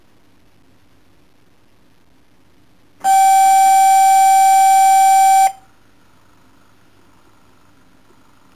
Der Wecker ist eine akustische Rufeinrichtung in einem eigenen Gehäuse.
Rufzeit          2-3 s oder
Dauerbetrieb bis zur Austastung, umschaltbar
Ruffrequenz      ca. 700 Hz